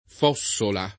Fossola [ f 0SS ola ]